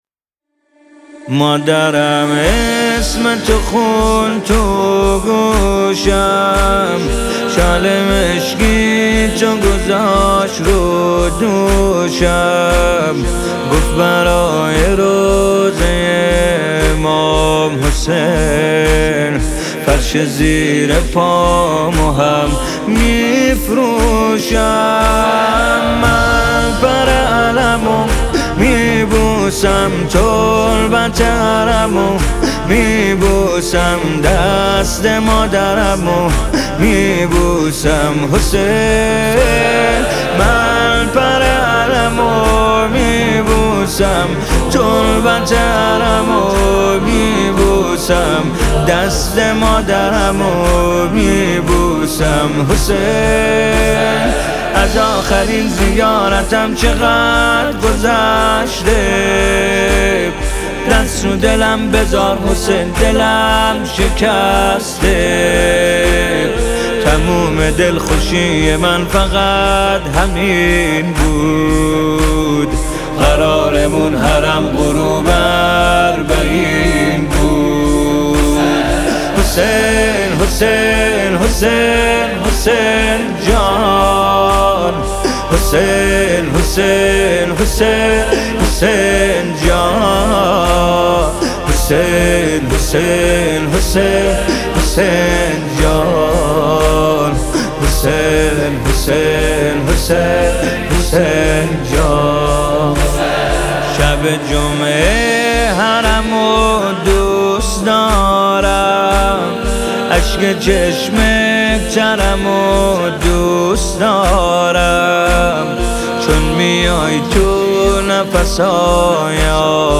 استودیویی